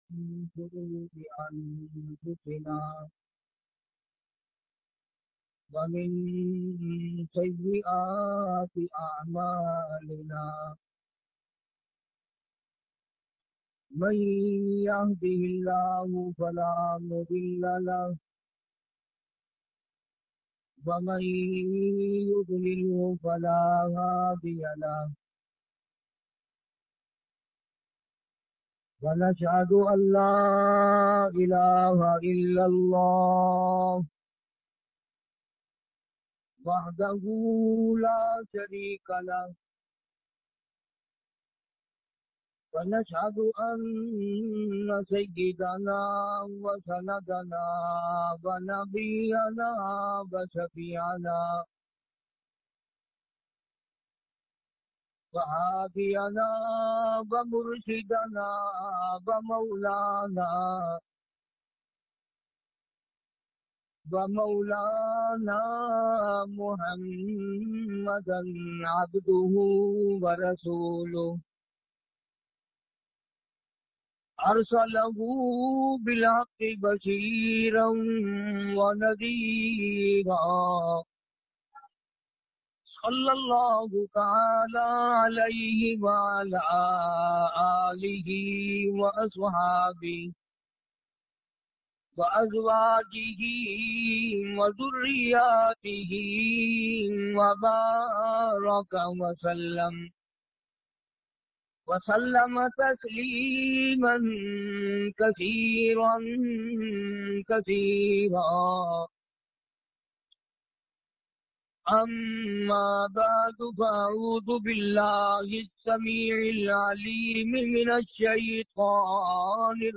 alam e rooh ki sardari bayan